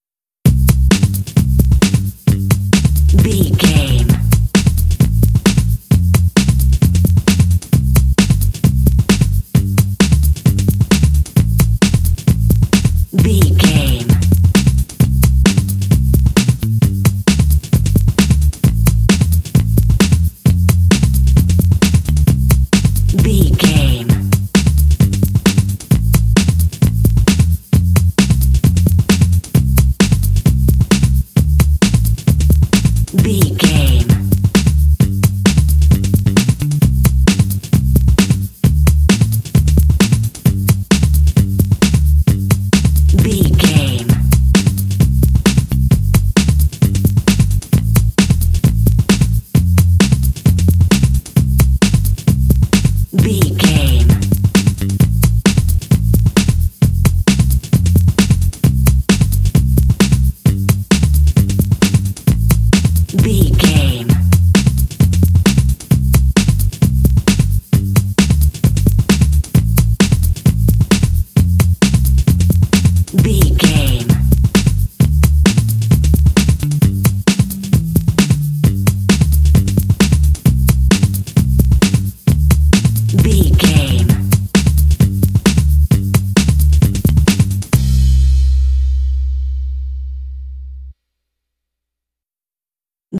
Ionian/Major
happy
fun
drums
bass guitar
playful